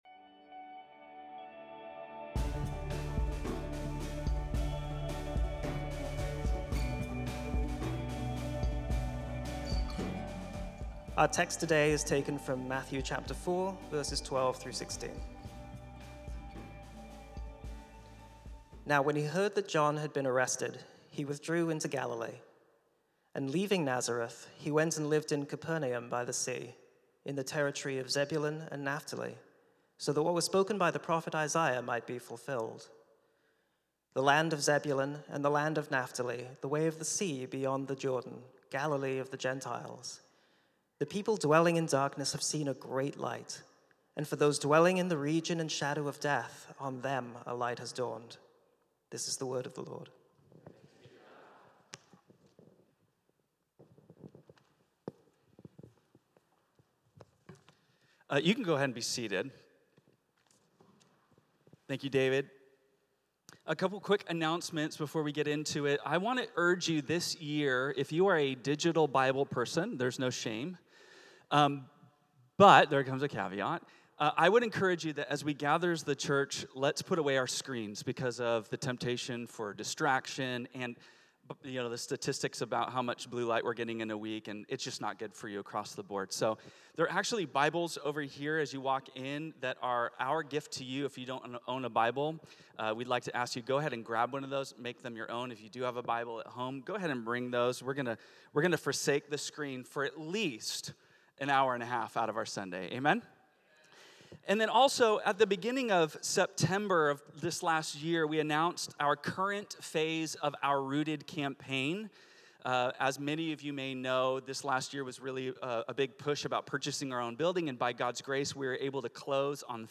Bible teachings from Reality Church Stockton.